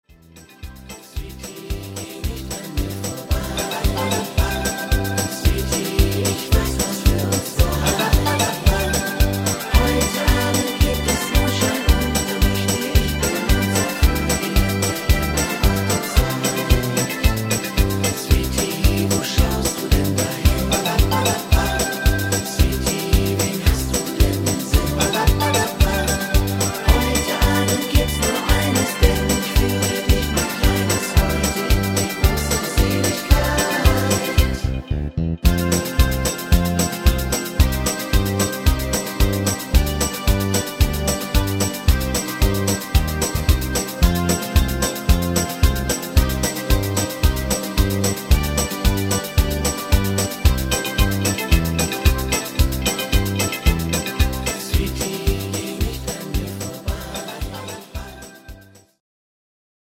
Rhythmus  Swingfox
Art  Deutsch, Oldies, Schlager 60er